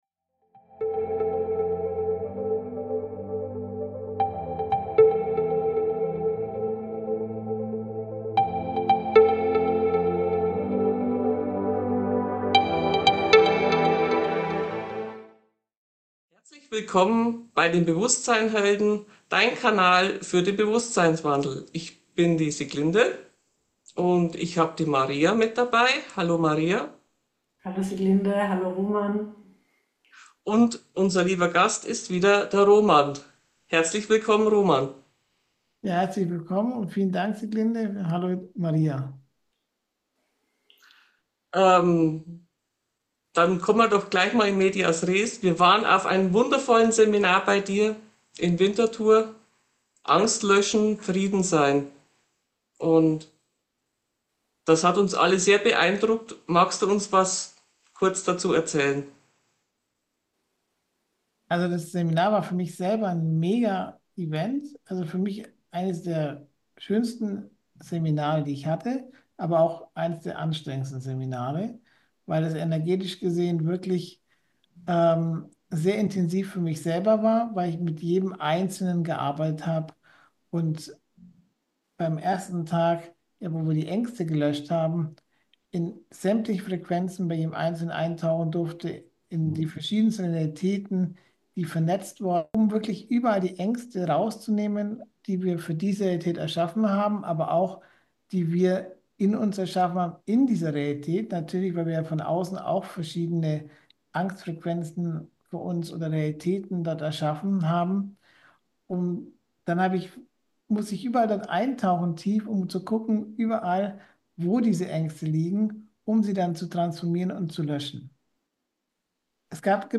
Wir berichten nicht nur von unseren eigenen Erlebnissen, sondern greifen auch Erfahrungen aus der Community auf. Dieses Gespräch ist eine Einladung für alle Teilnehmer, sich noch einmal intensiv auf den Frieden einzulassen und wertvolle Anregungen zu erhalten, wie wir als Schöpfer im Alltag damit umgehen können.